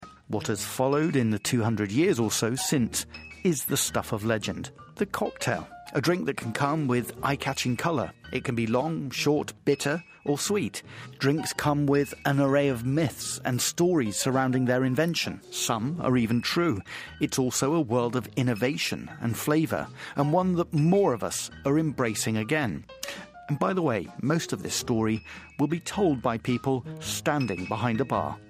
【英音模仿秀】鸡尾酒的故事 听力文件下载—在线英语听力室